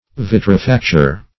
Search Result for " vitrifacture" : The Collaborative International Dictionary of English v.0.48: Vitrifacture \Vit`ri*fac"ture\ (?; 135), n. [L. vitrum glass + facere, factum, to make.] The manufacture of glass and glassware.
vitrifacture.mp3